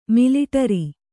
♪ miliṭari